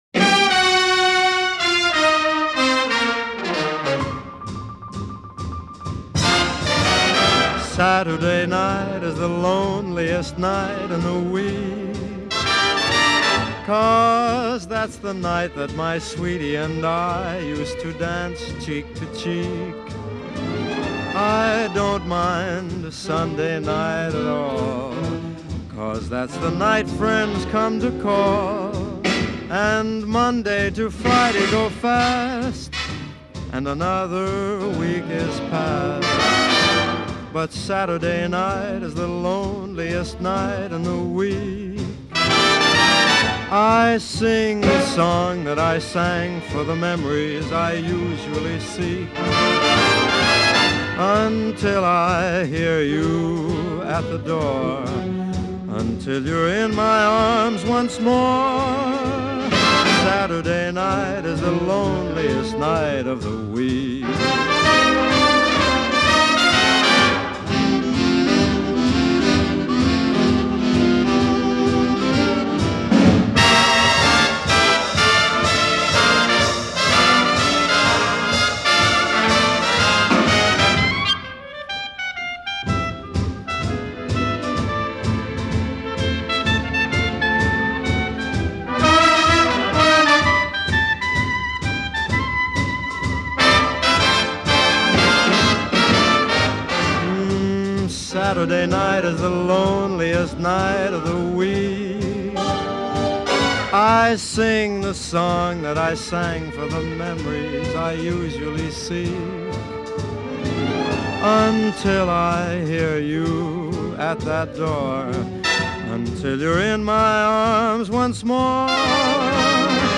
Genre: Traditional Pop, Vocal Jazz, Easy Listening